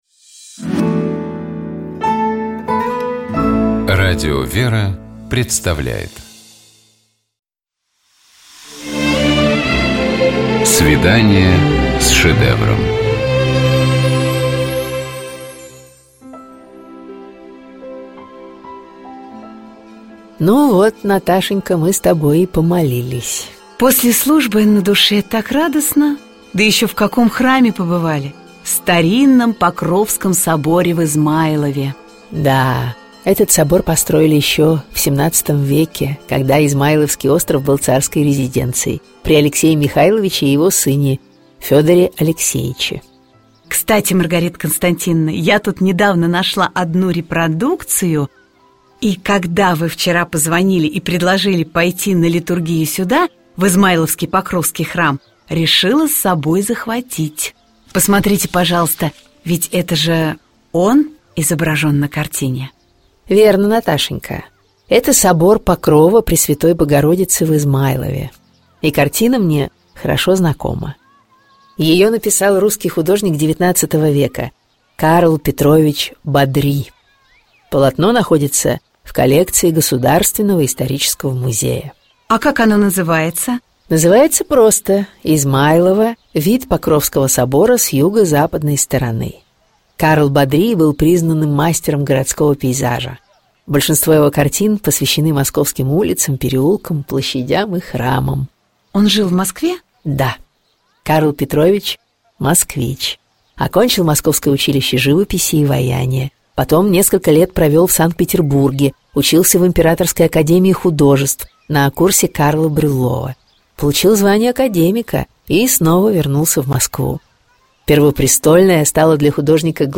Коридор картиной галереи с посетителями.